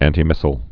(ăntē-mĭsəl, ăntī-)